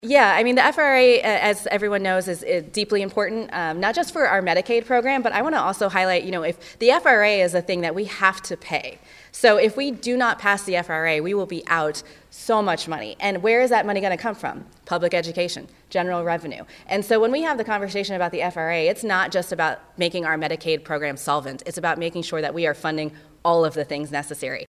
House Democrat leader Crystal Quade (D-Springfield) agreed,